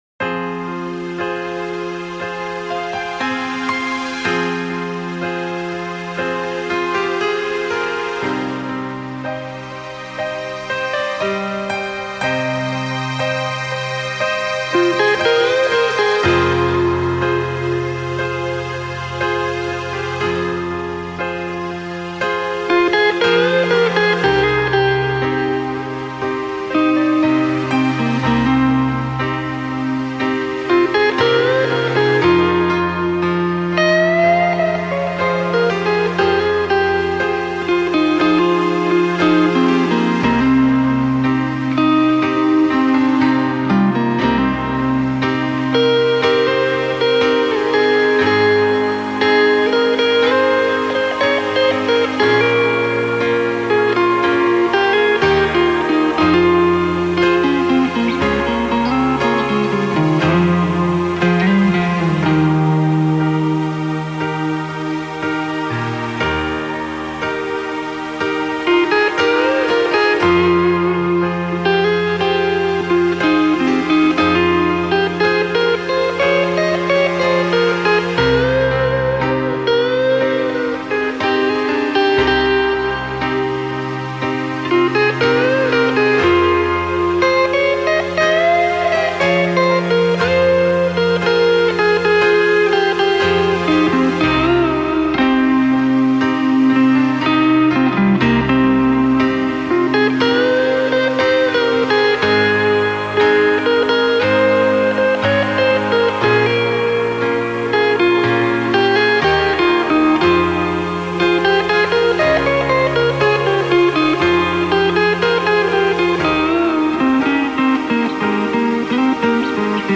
Жанр: classicmetal